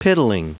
Prononciation du mot piddling en anglais (fichier audio)
Prononciation du mot : piddling